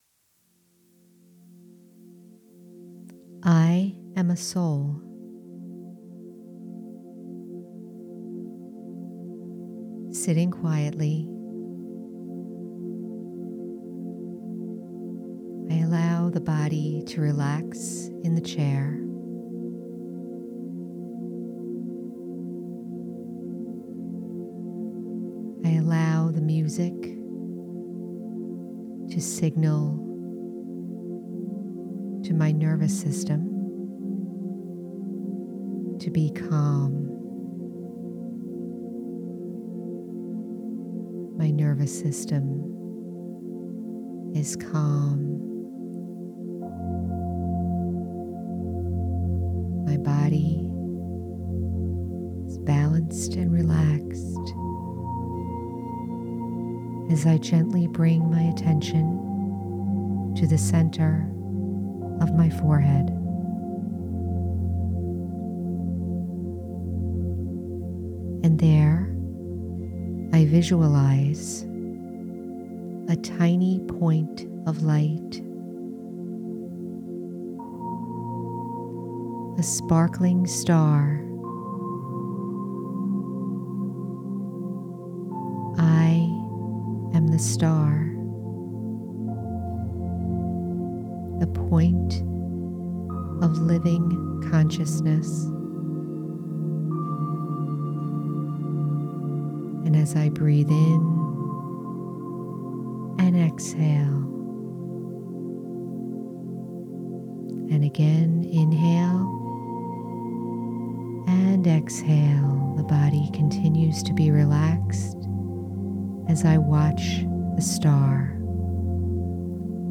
Experience the pure qualities of the self, the soul, with this gentle guided meditation and soft music.